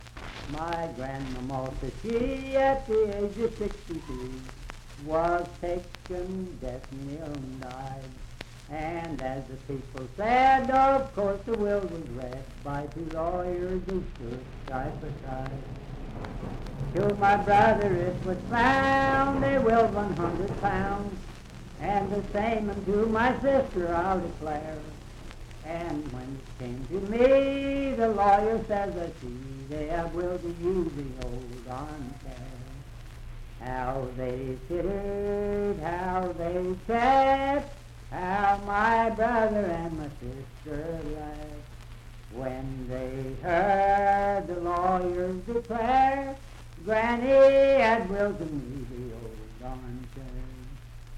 Unaccompanied vocal music and folktales
Verse-refrain 3(8) & R(4).
Voice (sung)
Wood County (W. Va.), Parkersburg (W. Va.)